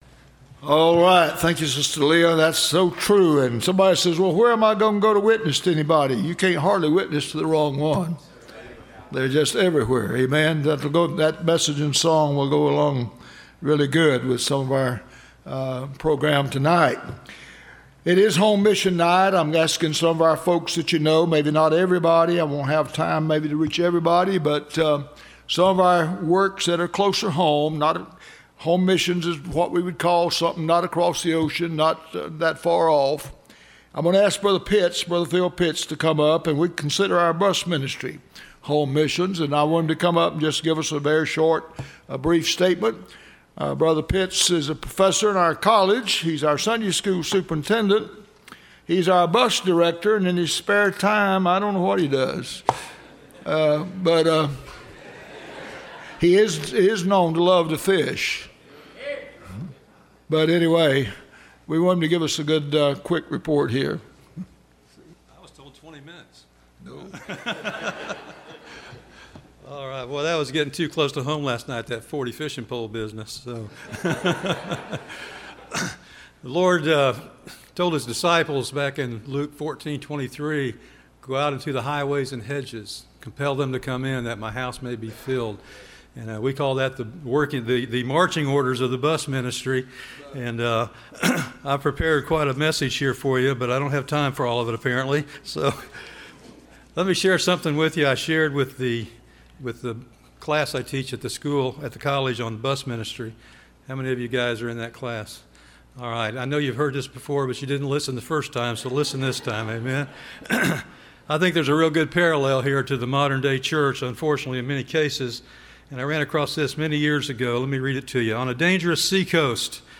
Listen to Message
Service Type: Bible Conference